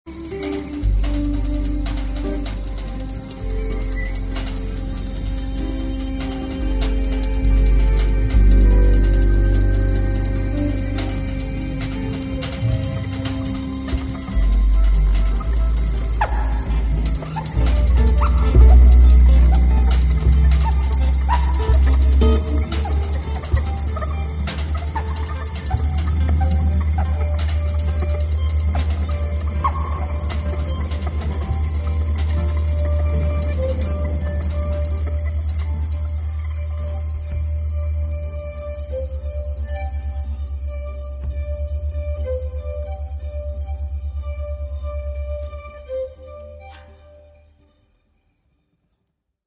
Aufgenommen am 12.12.04 im Porgy & Bess Wien